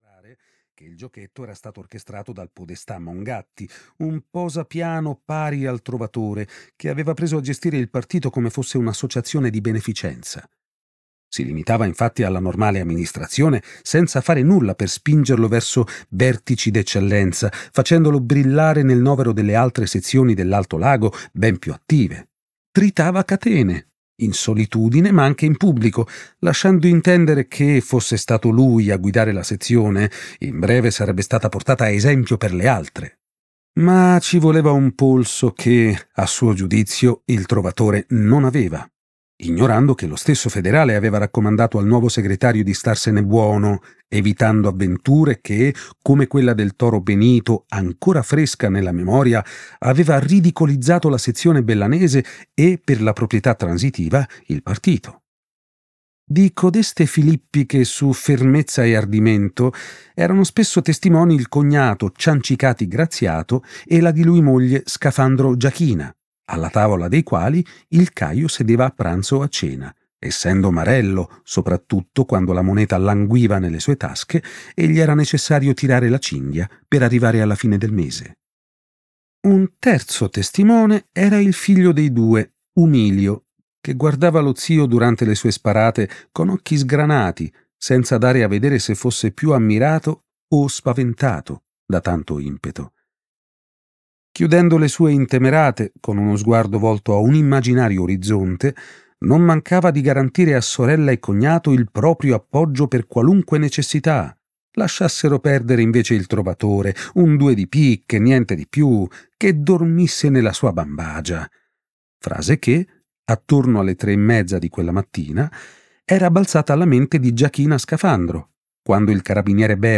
"Il sistema Vivacchia" di Andrea Vitali - Audiolibro digitale - AUDIOLIBRI LIQUIDI - Il Libraio